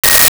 Sci Fi Beep 01
Sci Fi Beep 01.wav